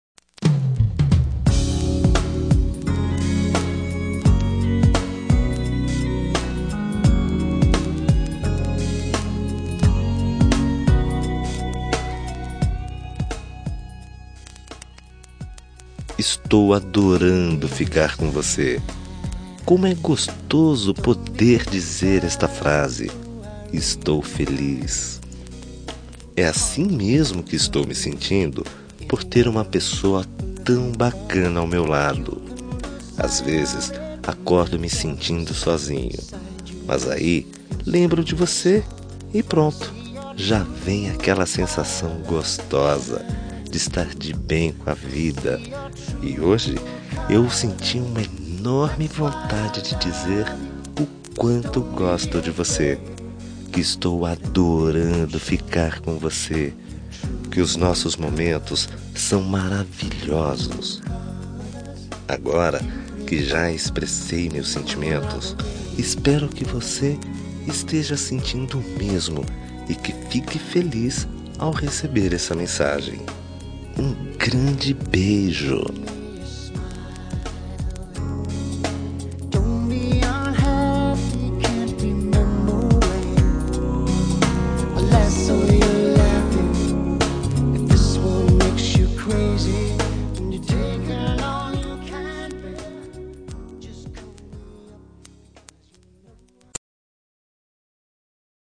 Telemensagem Estou Adorando ficar com Você – Voz Masculina – Cód: 757 – Ficante – Lindaaa